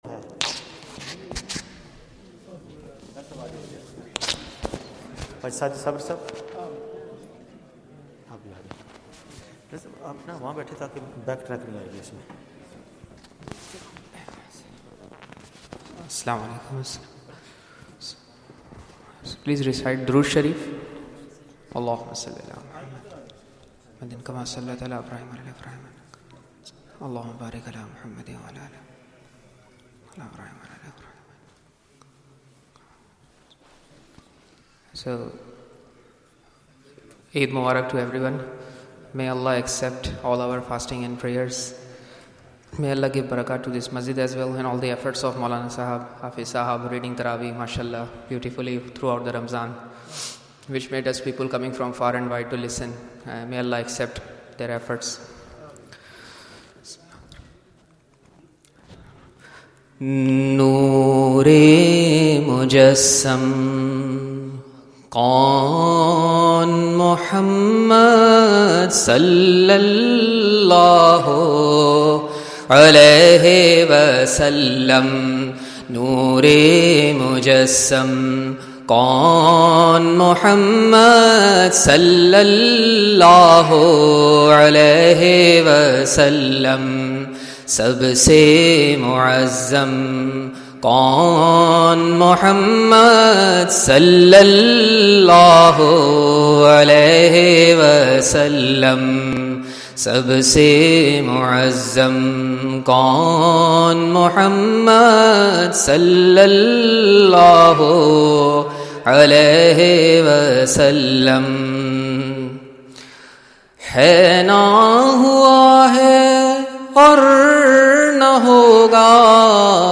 Eid ul Fitr day Naat Mehfil